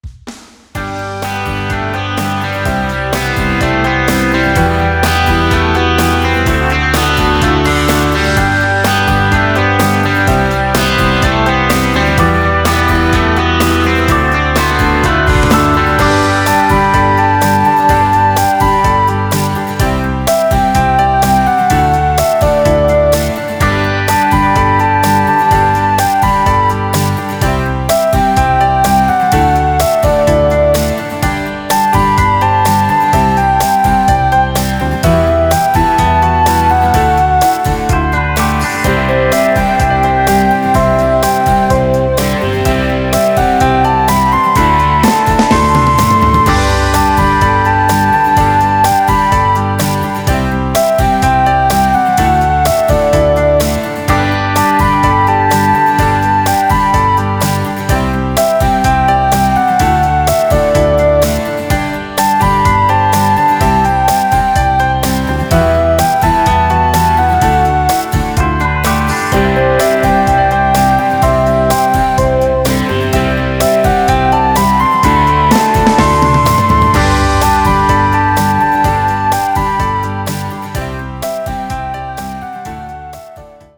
Singing Calls